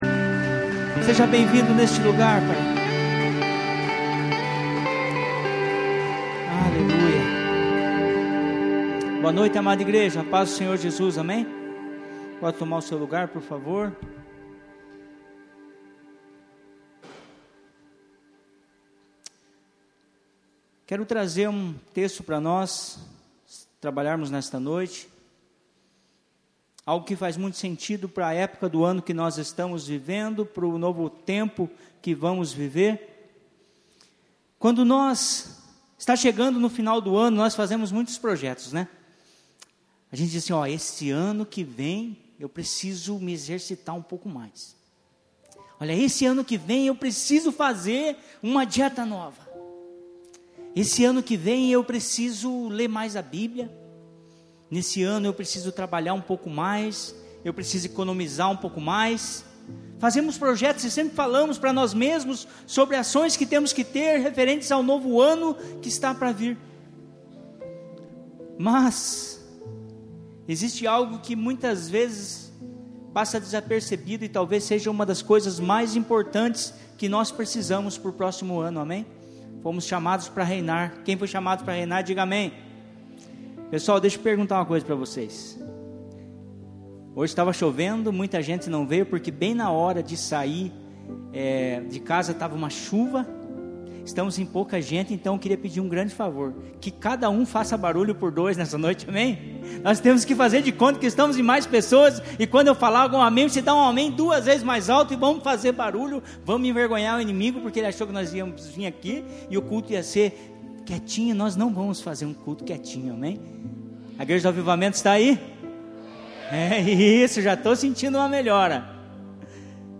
ICP - Igreja Cristã Presbiteriana